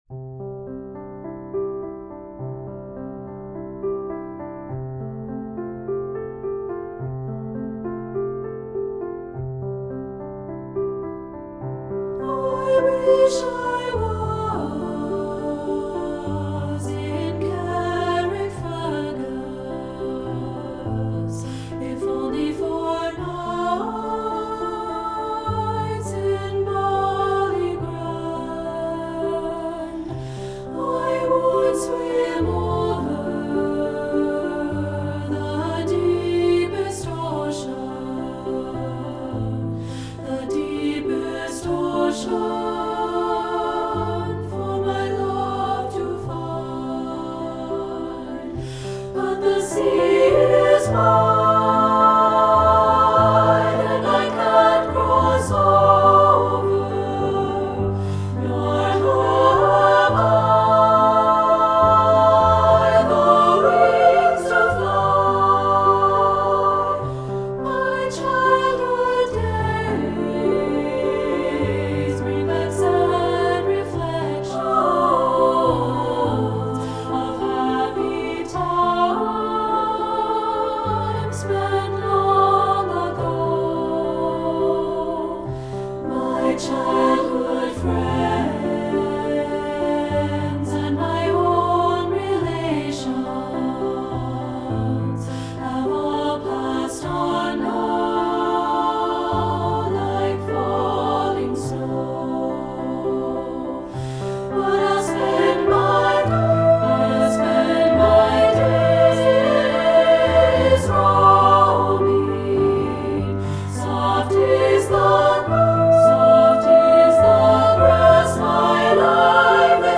Composer: Irish Folk Song
Voicing: SSA and Piano